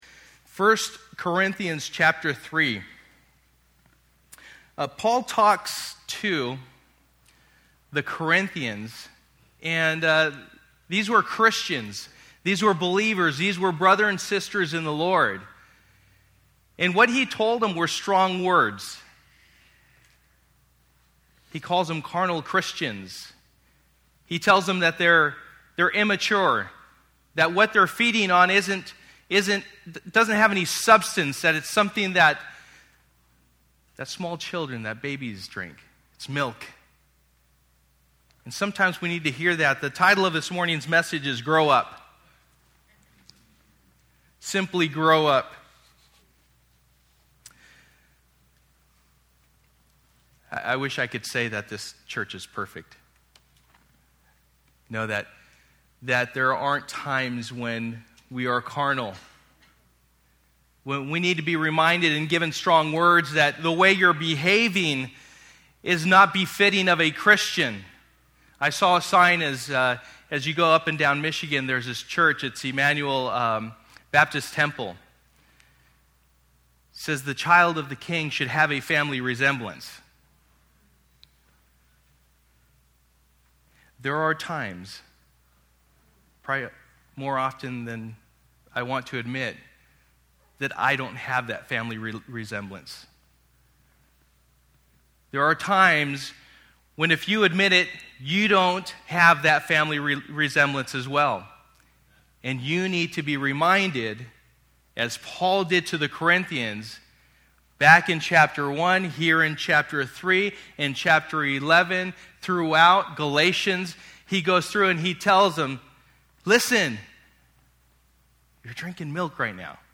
Sold Out Passage: 1 Corinthians 3:1-23 Service: Sunday Morning %todo_render% « Sold Out